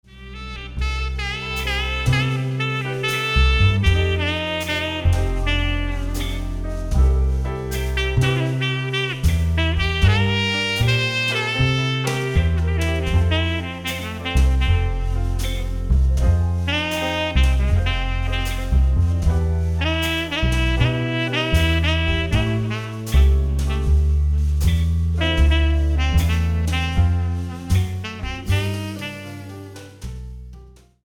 78 BPM